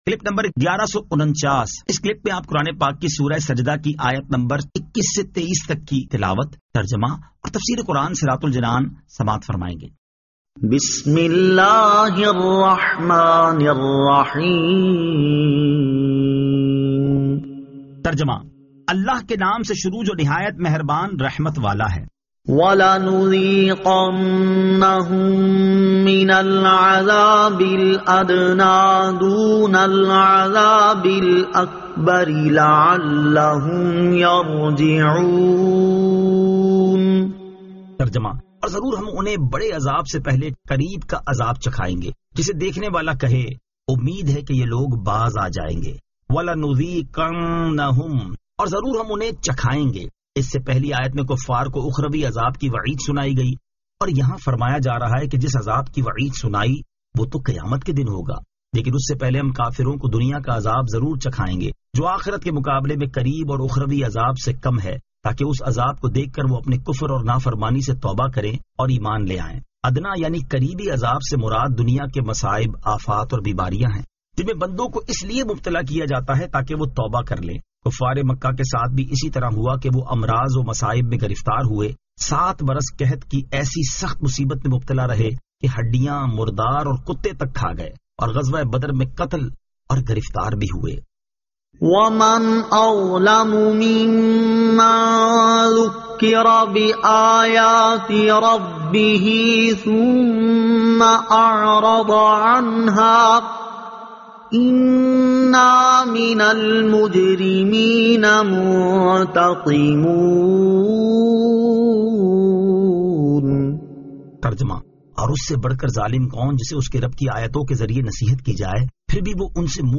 Surah As-Sajda 21 To 23 Tilawat , Tarjama , Tafseer